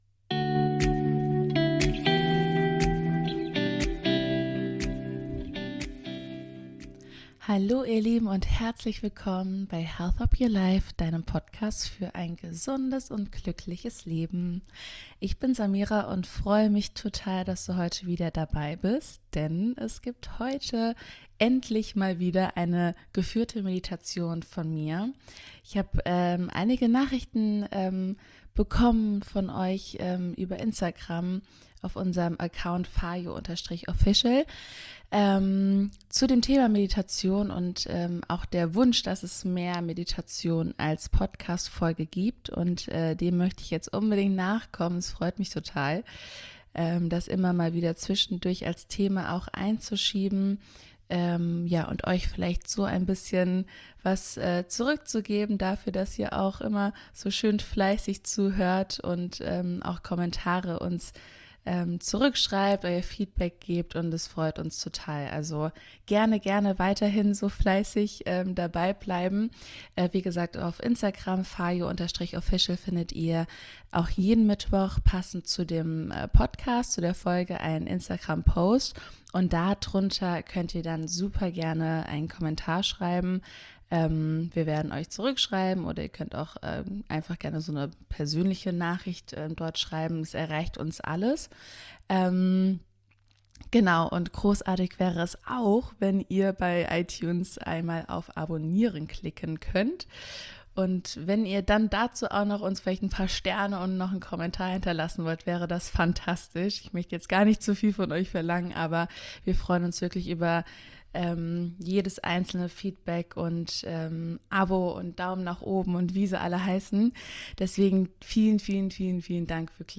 #021 Geführte Meditation für mehr Leichtigkeit